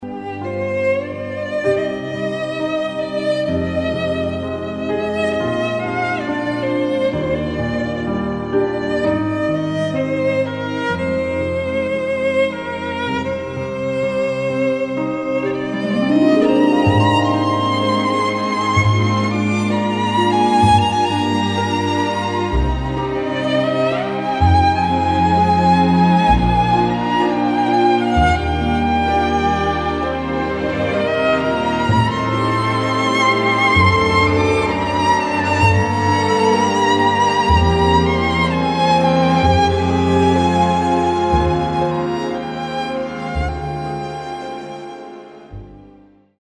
• Качество: 128, Stereo
красивые
без слов
скрипка
инструментальные
пианино
лиричные
Классическая мелодия